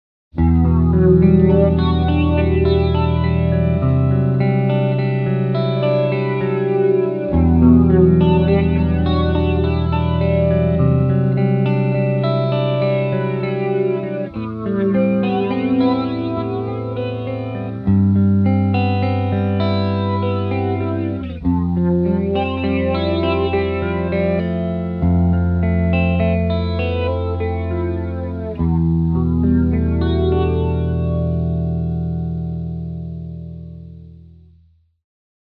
Wah Pedal
- Vintage Inductor-Transistor Wah
Demo with Humbucker Pickup and Drive channel